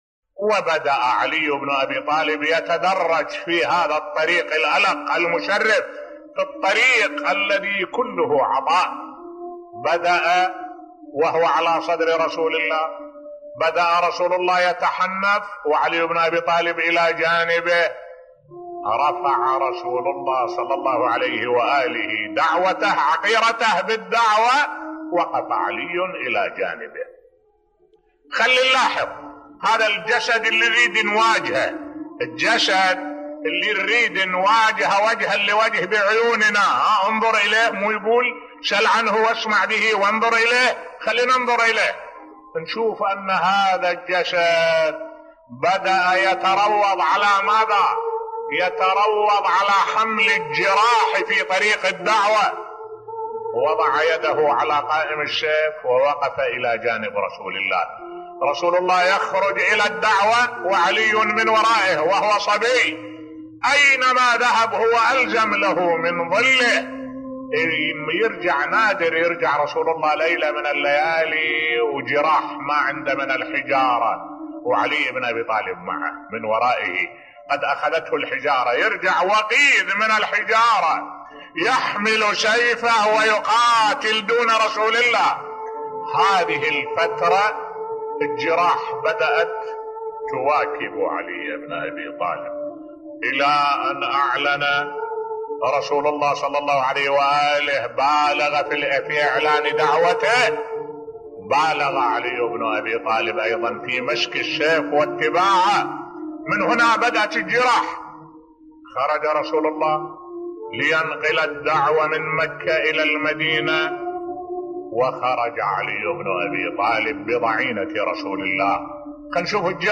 ملف صوتی كيف كانت بدايات أمير المؤمنين علي (ع) في حياته مع رسول الله بصوت الشيخ الدكتور أحمد الوائلي